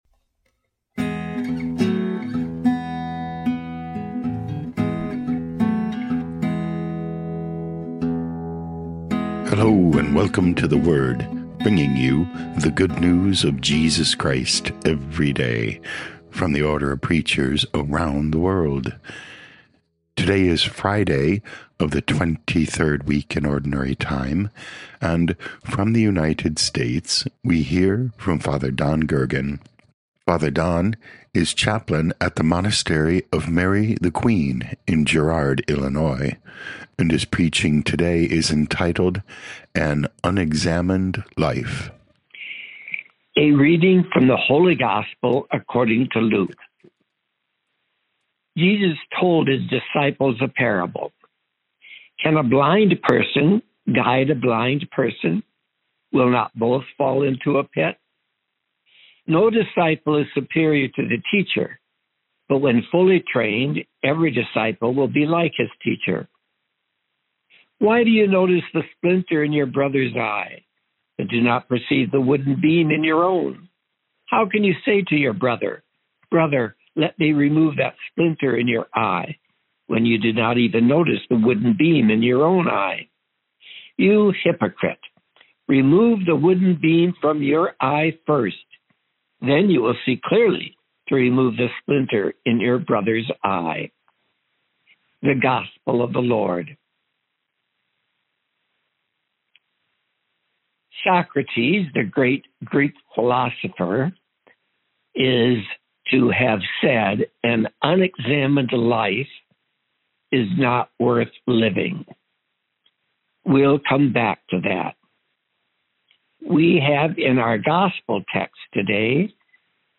12 Sep 2025 An Unexamined Life Podcast: Play in new window | Download For 12 September 2025, Friday of week 23 in Ordinary Time, based on Luke 6:39-42, sent in from Girard, Illinois, USA.
Preaching